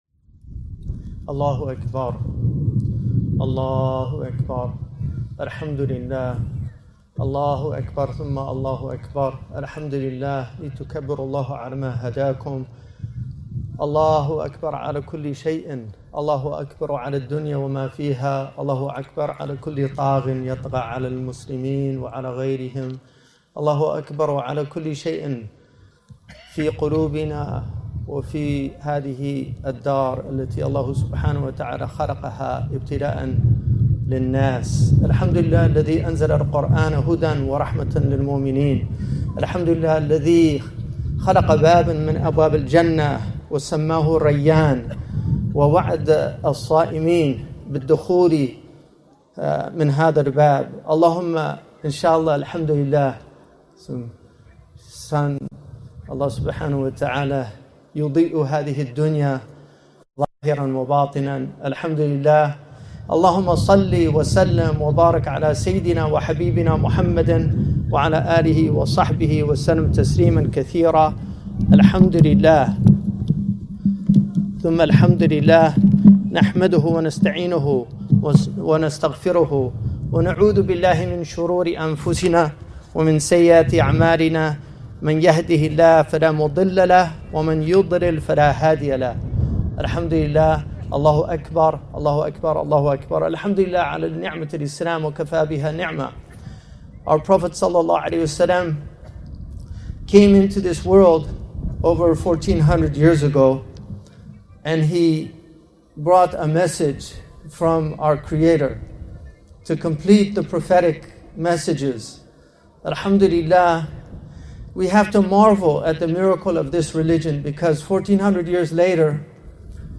Eid al-Fitr Khutbah 2022 - Days of Gratitude - Shaykh Hamza Yusuf.mp3